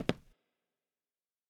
FootstepW3Right-12db.wav